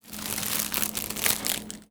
R - Foley 84.wav